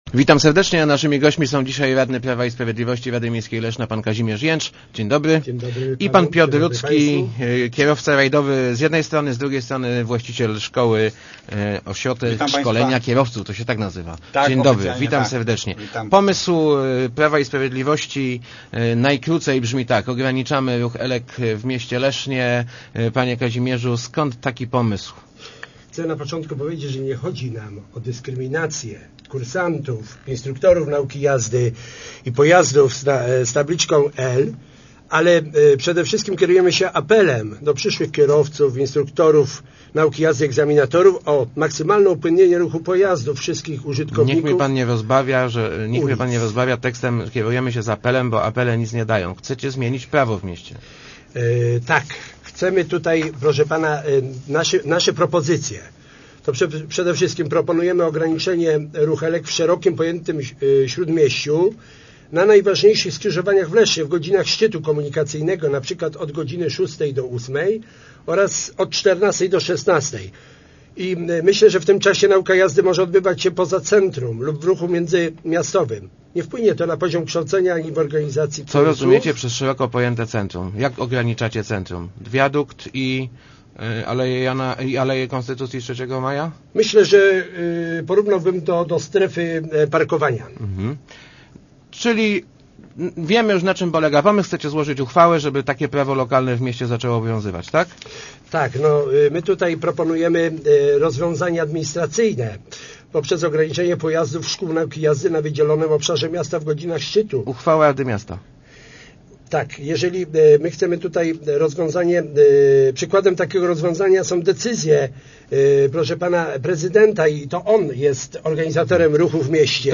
Nie jeste�my przeciwni nauce jazdy – przekonywa� w Rozmowach Elki radny PiS Kazimierz J�cz. Jego klub chce ograniczy� ruch „elek” w �ródmie�ciu w godzinach szczytu.